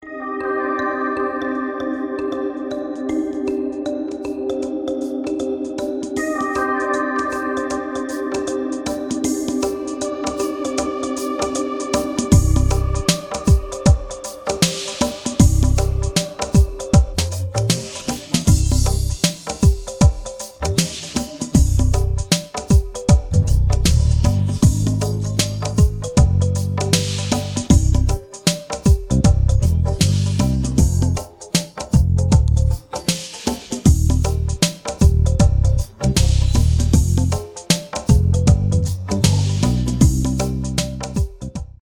шансон
без слов